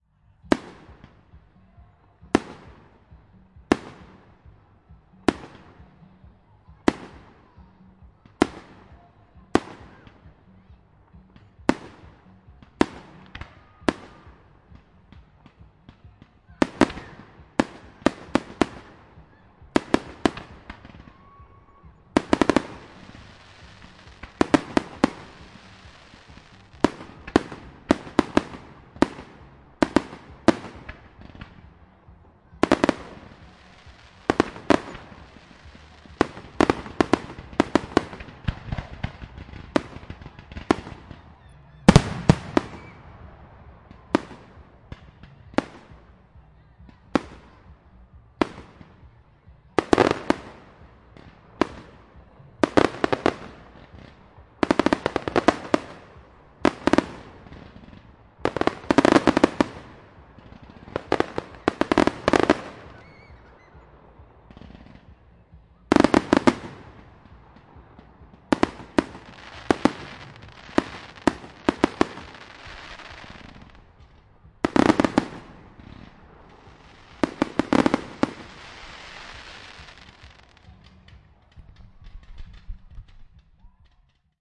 记录仪的比较 " 烟花, 关闭, D ( H1)
描述：烟花的未加工的音频显示在Godalming，英国。我用Zoom H1和Zoom H4n Pro同时录制了这个事件来比较质量。令人讨厌的是，组织者还在活动期间抨击了音乐，因此安静的时刻被遥远的，虽然模糊不清的音乐所污染。